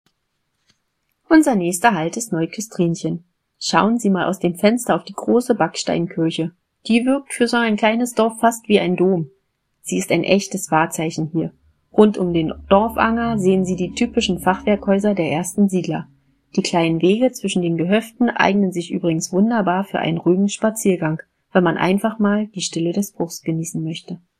Audio Guide